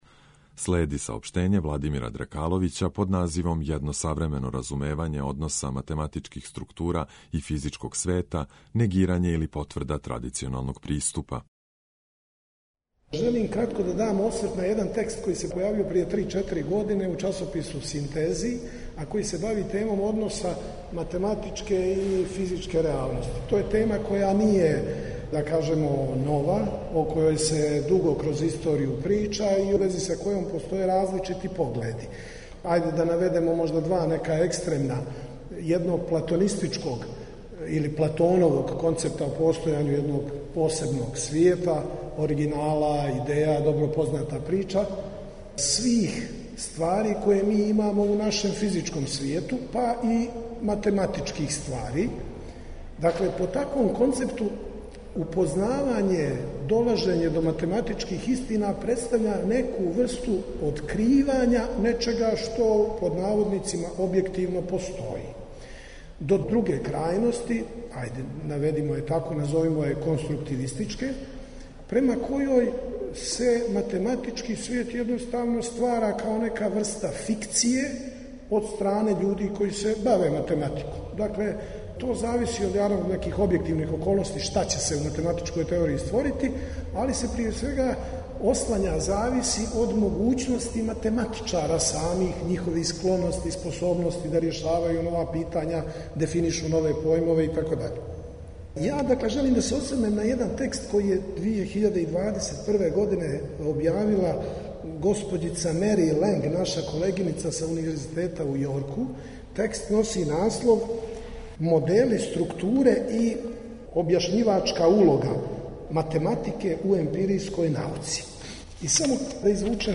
Skup je tradicionalno održan u izuzetnom istorijskom ambijentu Sremskih Karlovaca i Karlovačke gimnazije, prve srpske gimnazije.